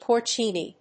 音節por・cine 発音記号・読み方
/pˈɔɚsɑɪn(米国英語), pˈɔːsɑɪn(英国英語)/